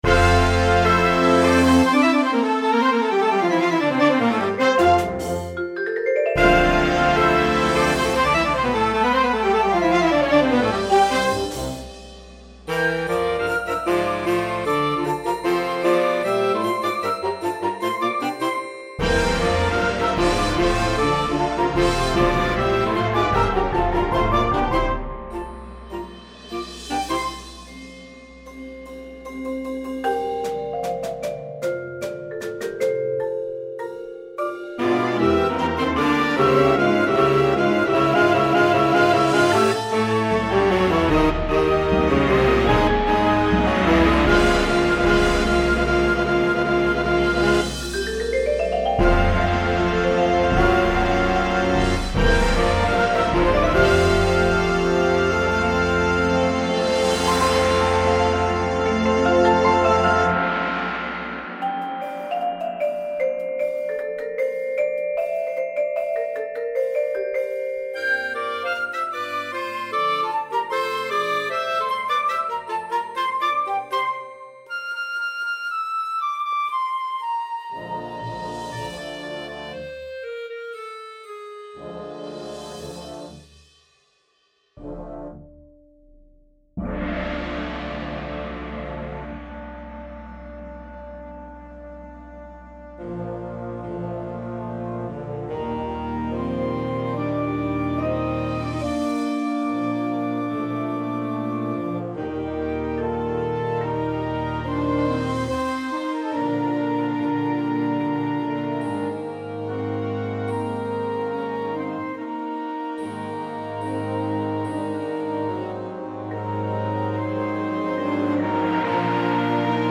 • Flute
• Clarinet 1, 2
• Alto Saxophone
• Trumpet 1, 2, 3
• Horn in F
• Trombone 1, 2
• Tuba
• Front Ensemble
• Synthesizers
• Aux. Percussion